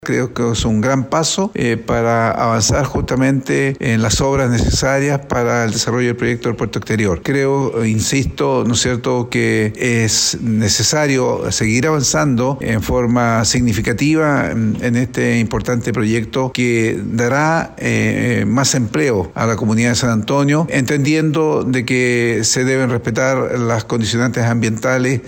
Con respecto al inicio de la licitación, el alcalde de la comuna de San Antonio, Omar Vera, señaló que es un gran paso para avanzar en las obras necesarias y que se deben respetar las condicionantes ambientales.